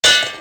shipattach.ogg